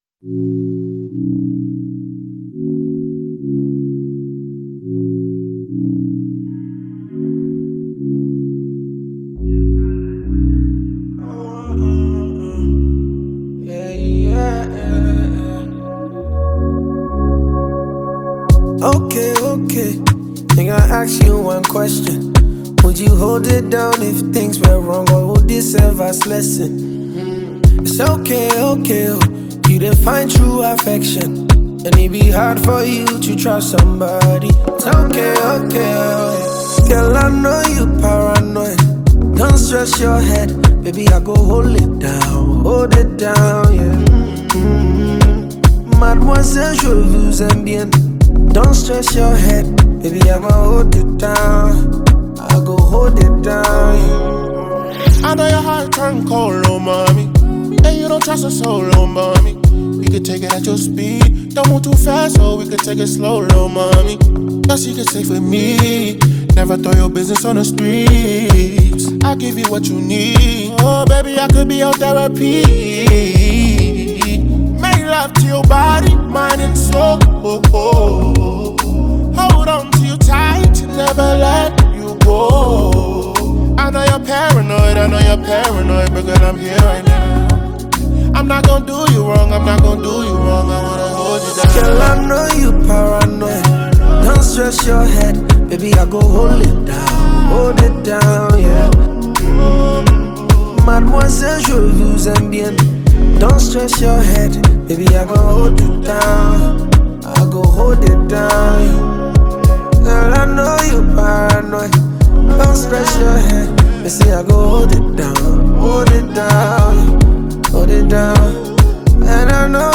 catchy rhythms and stirring lyrics